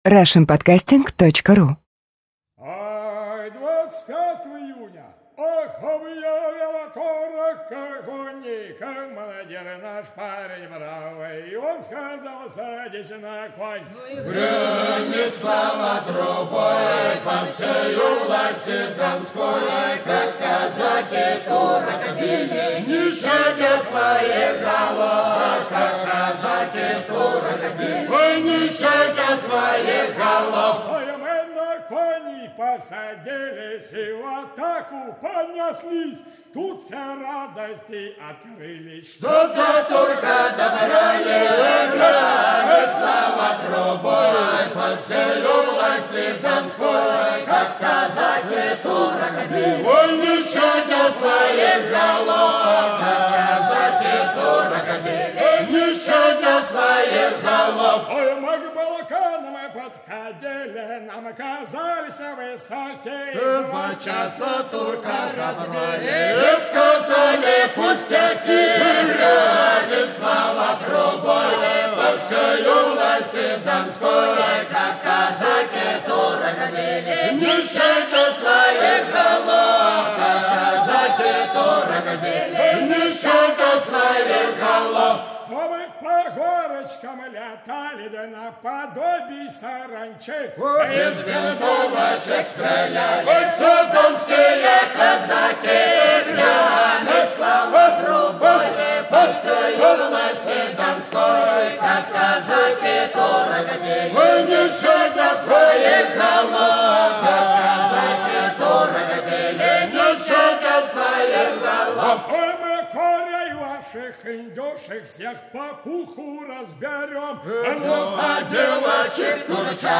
Старинная казачья песня.